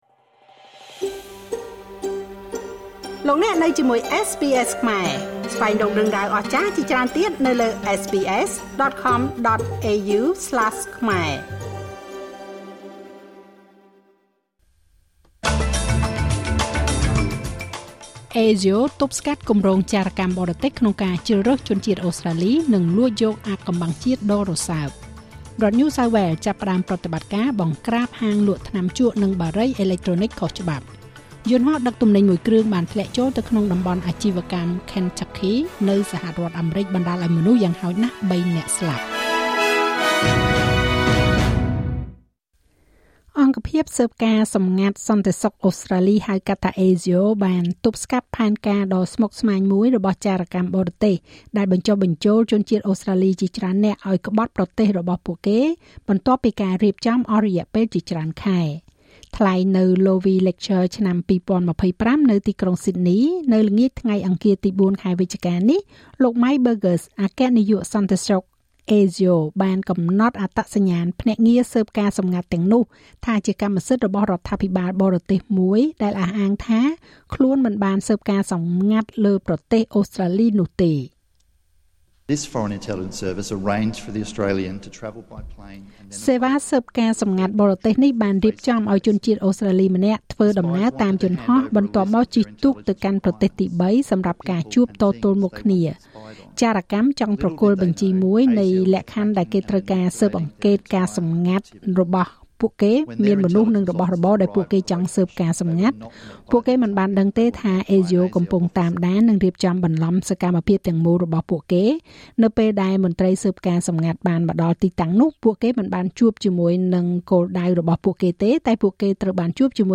នាទីព័ត៌មានរបស់SBSខ្មែរ សម្រាប់ថ្ងៃពុធ ទី៥ ខែវិច្ឆិកា ឆ្នាំ២០២៥